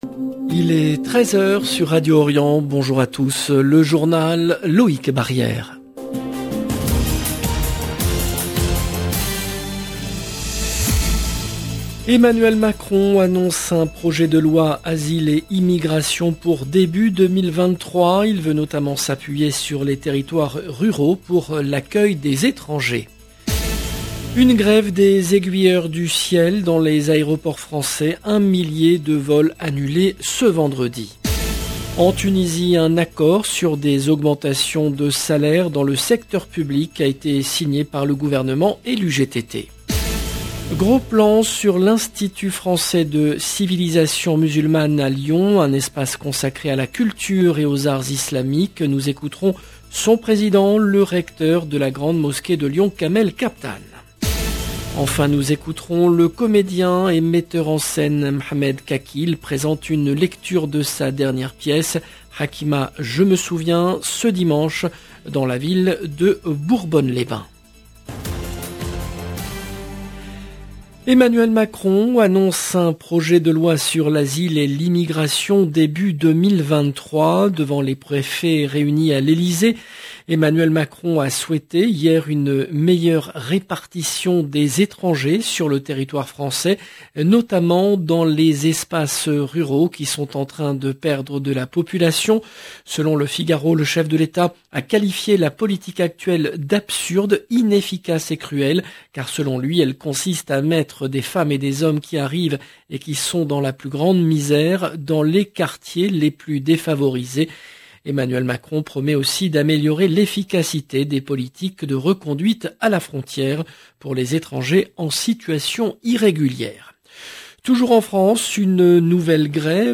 LE JOURNAL EN LANGUE FRANCAISE DE 13H DU 16/09/22